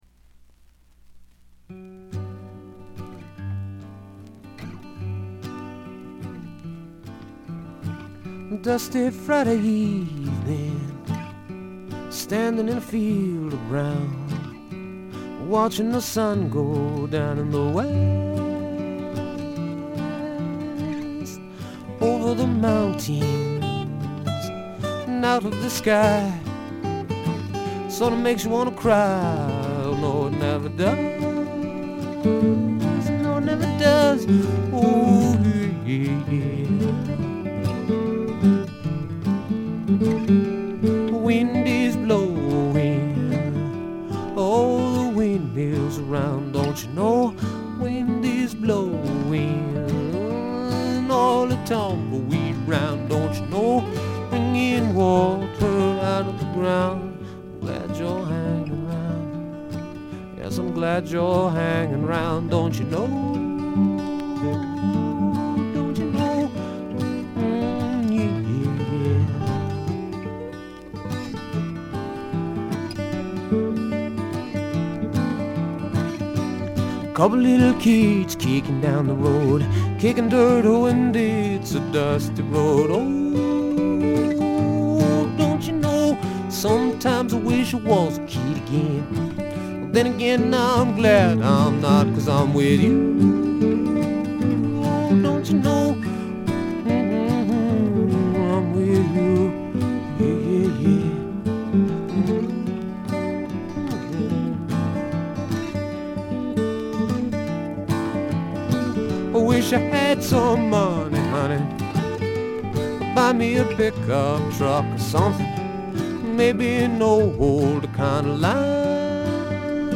プレスのせいかチリプチ少々出ます。
本人のギター弾き語りを基本に友人たちによるごくシンプルなバックが付くだけのフォーキーな作品です。
とてもおだやかでドリーミーな感覚もがただようフォーク作品です。
試聴曲は現品からの取り込み音源です。
guitar